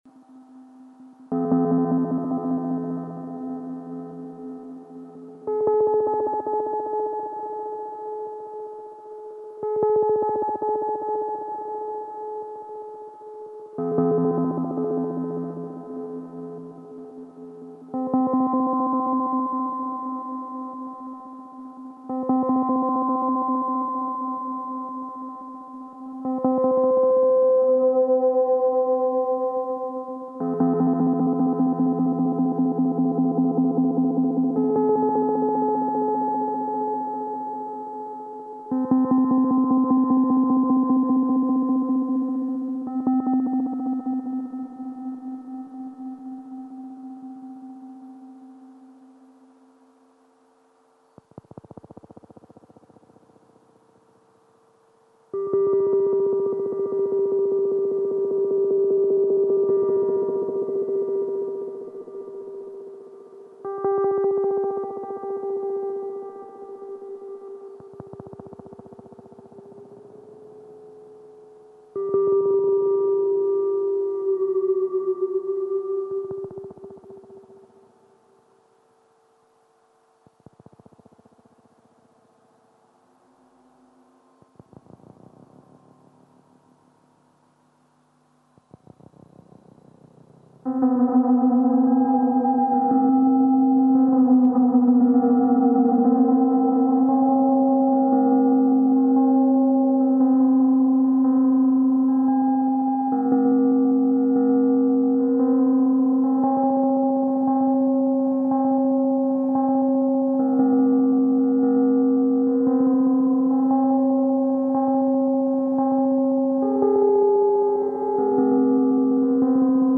Circuit Bent Talk and Learn Toy (listen)
Like most circuit bends this produces all kinds of random things. The knob acts as pitch control and the red button activates the 'glitch'